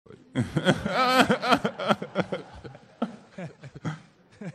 PLAY kawhi leonard laugh
kawhi-leonard-media-day-press-conference-with-kawhi-laugh-espn_cutted.mp3